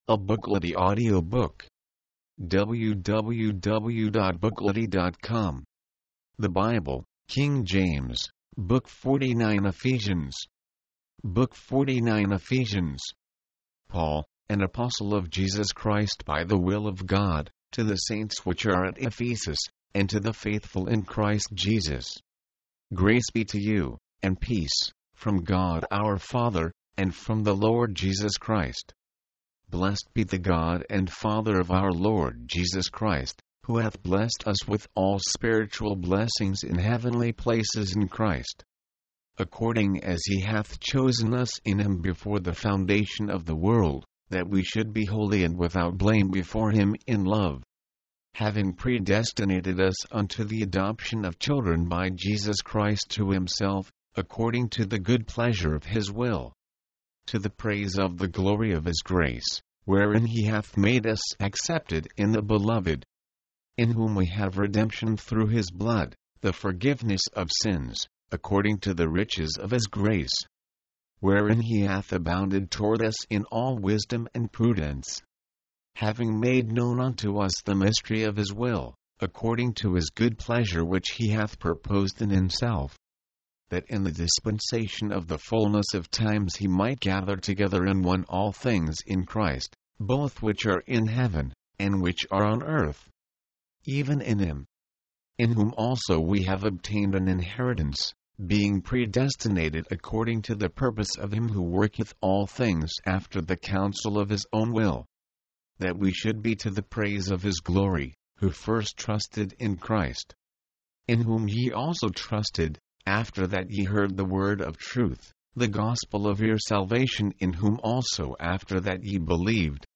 Ephesians Ephesians The Holy Bible: King James Version mp3, audiobook, audio, book Date Added: Dec/31/1969 Rating: 2.